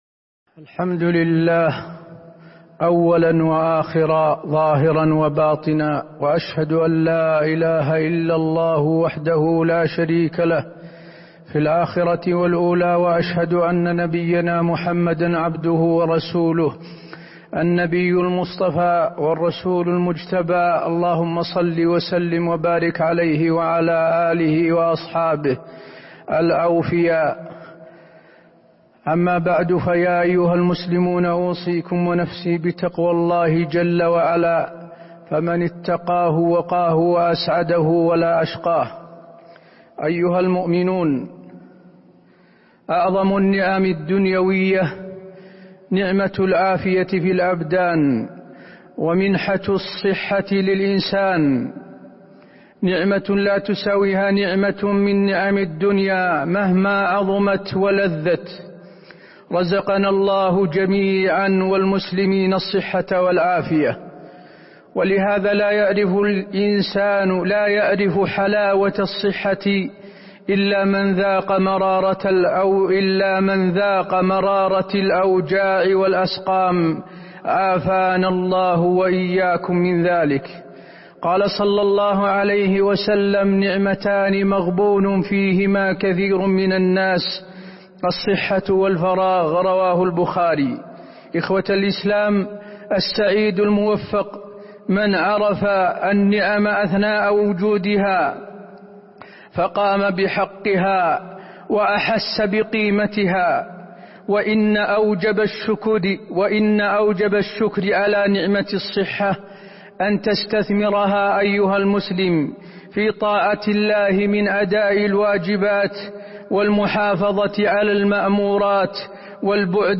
تاريخ النشر ٥ ذو القعدة ١٤٤١ هـ المكان: المسجد النبوي الشيخ: فضيلة الشيخ د. حسين بن عبدالعزيز آل الشيخ فضيلة الشيخ د. حسين بن عبدالعزيز آل الشيخ نعمة الصحة